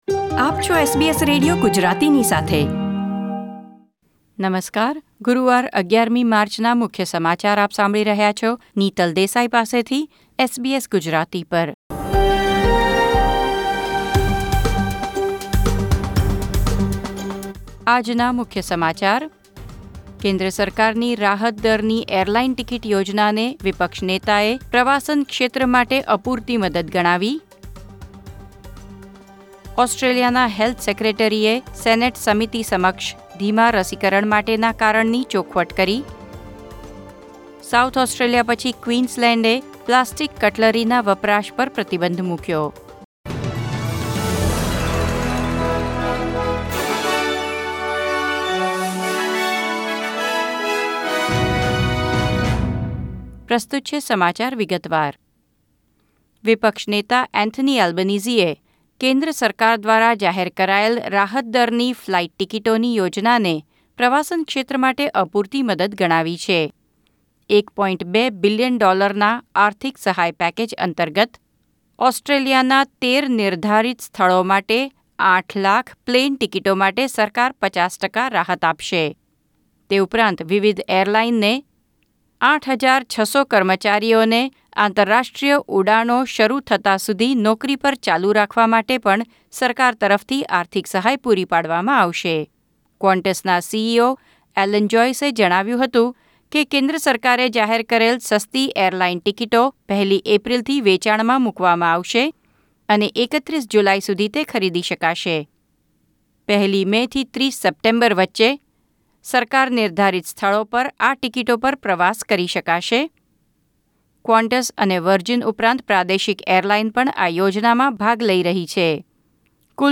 SBS Gujarati News Bulletin 11 March 2021